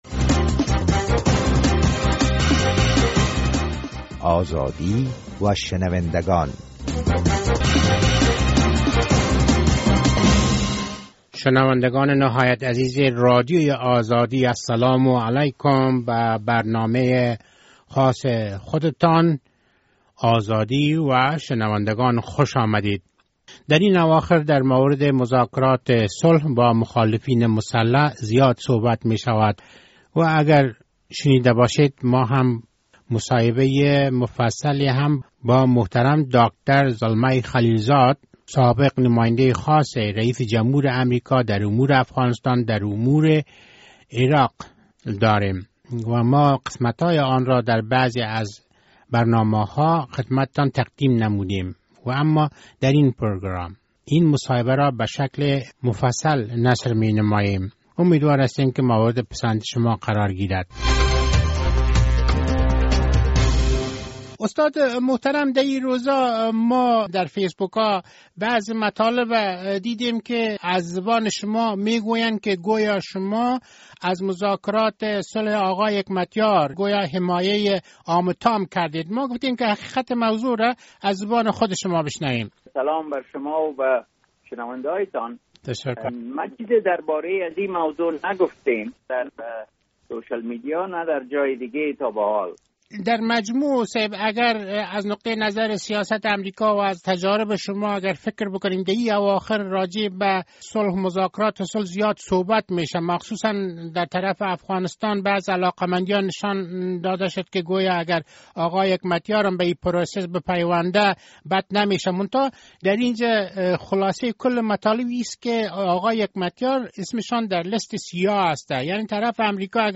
مصاحبه اختصاصی داکتر زلمی خلیل زاد